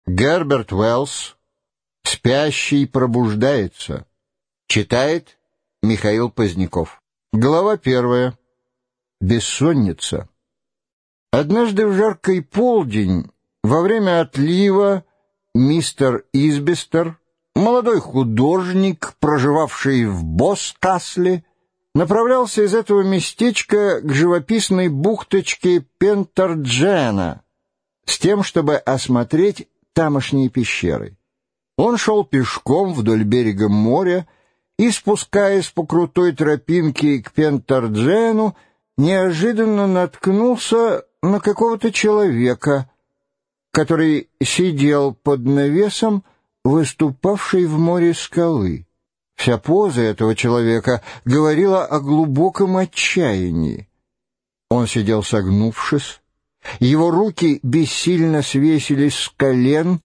Аудиокнига Спящий пробуждается | Библиотека аудиокниг
Прослушать и бесплатно скачать фрагмент аудиокниги